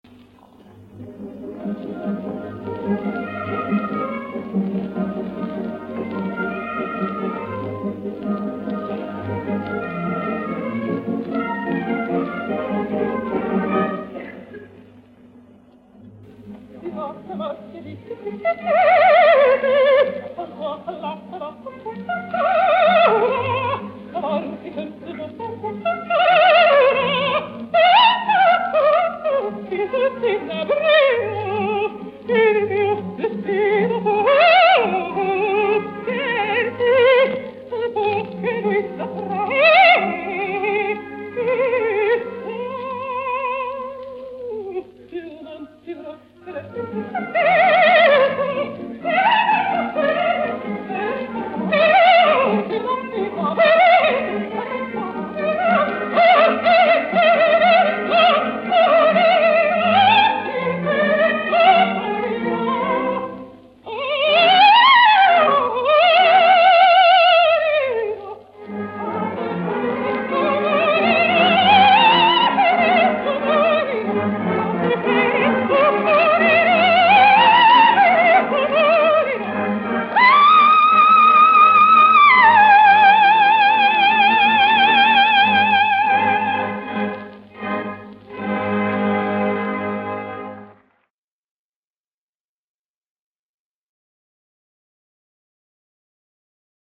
★橫跨花腔、戲劇、抒情和重抒情領域高水準演出
歌劇女神的寬廣音域和歌聲中強烈的戲劇張力，在此發輝的淋漓盡致。